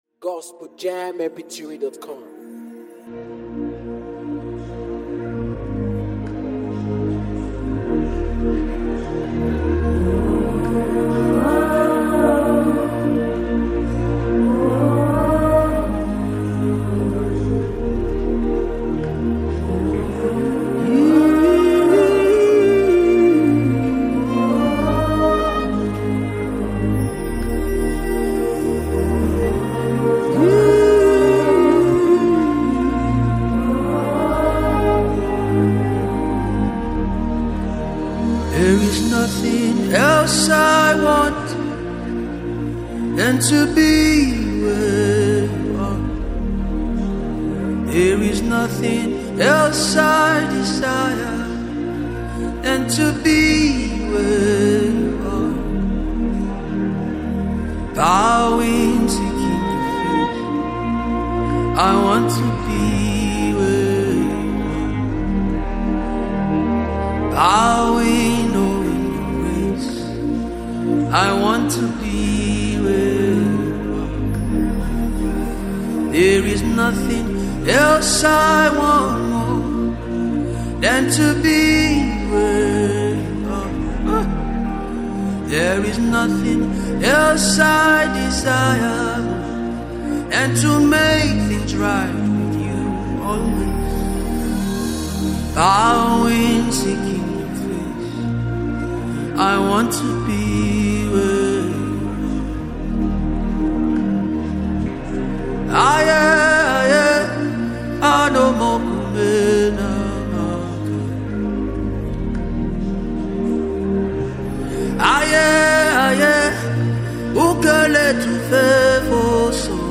African praiseAfro beatmusic